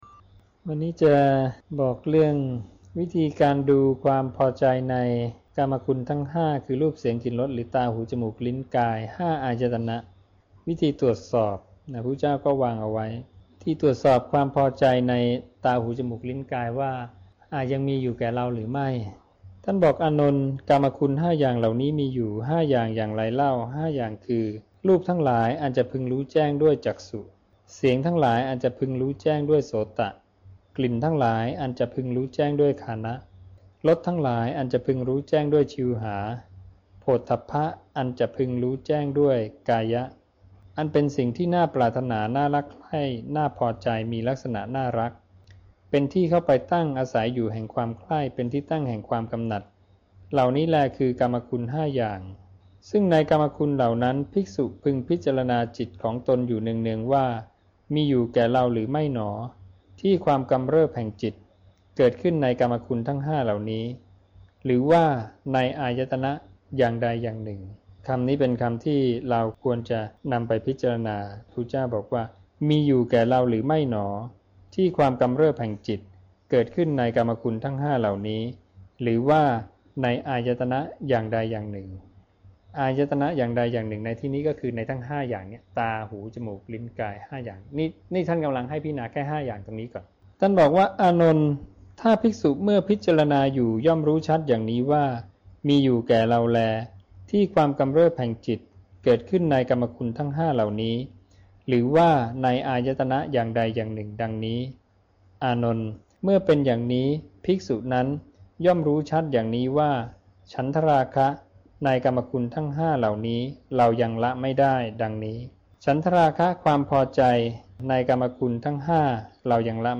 วัดนาป่าพง ลำลูกกา คลอง ๑๐ ปทุมธานี